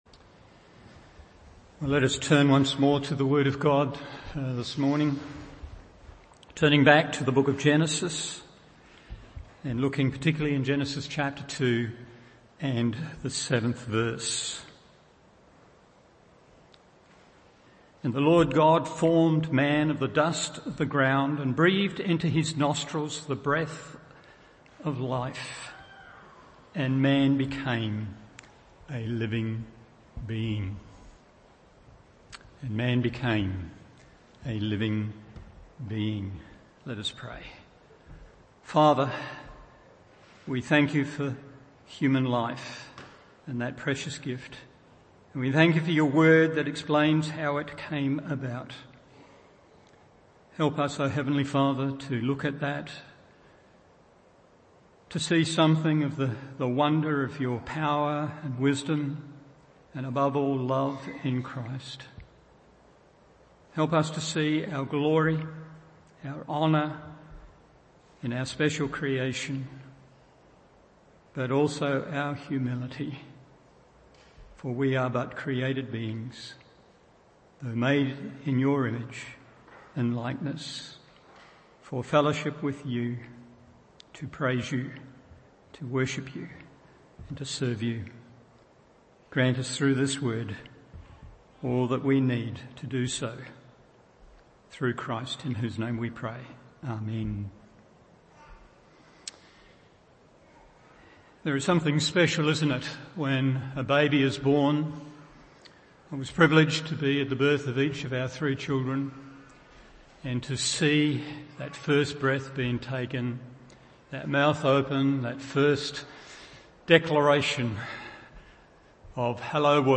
Morning Service Genesis 2:7 1. A Body Made from the Dust 2. A Soul Given from God 3. Bound by a Slender Bond…